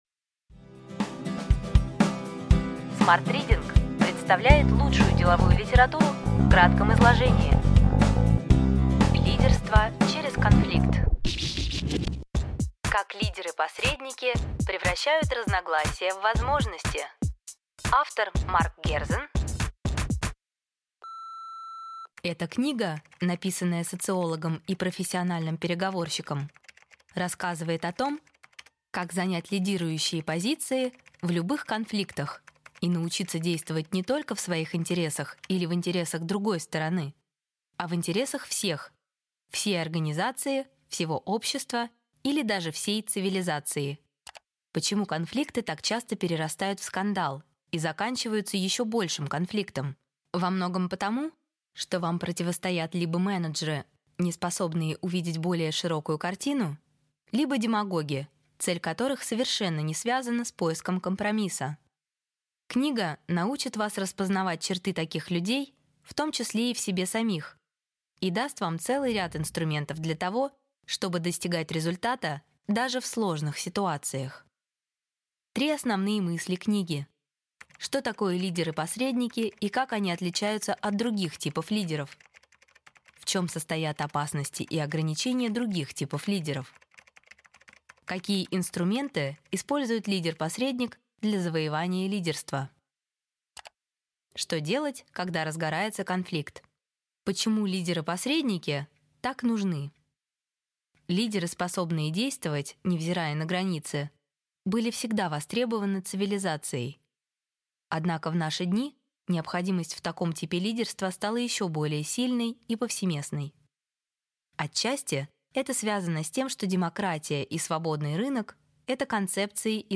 Аудиокнига Ключевые идеи книги: Лидерство через конфликт. Как лидеры-посредники превращают разногласия в возможности.